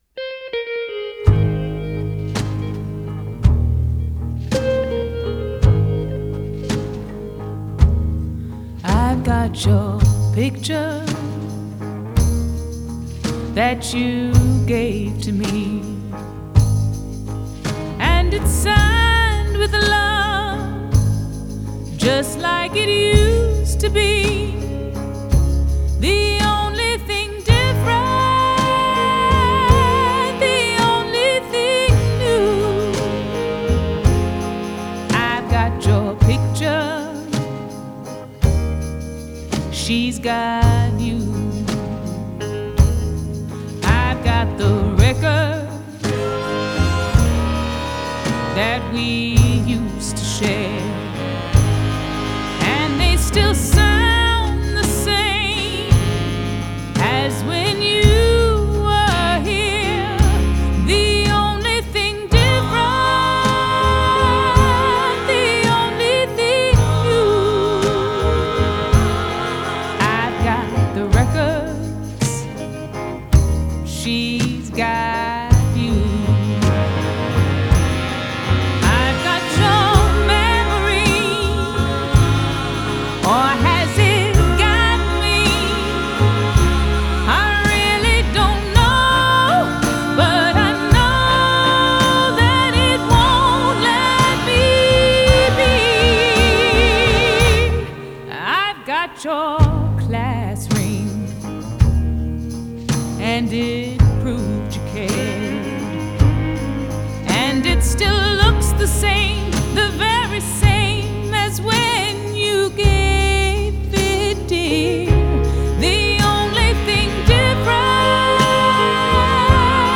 as she danced, strummed, sang!